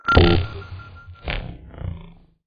UI_SFX_Pack_61_55.wav